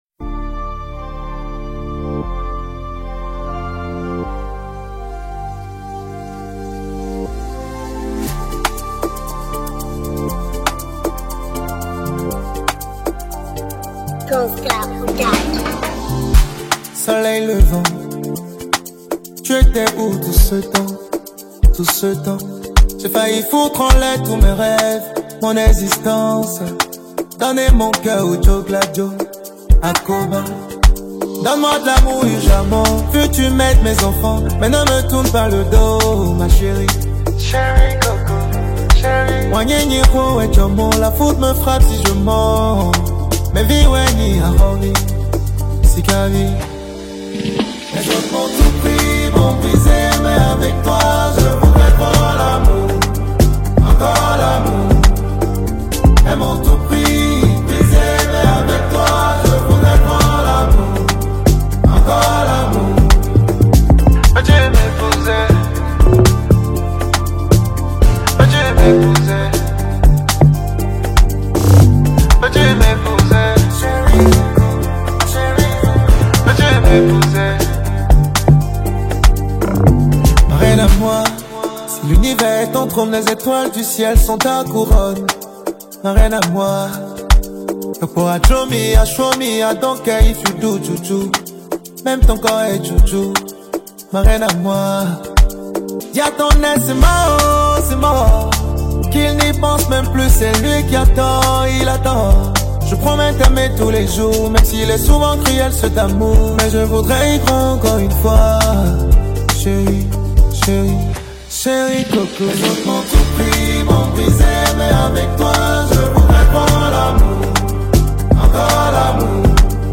| Afro Music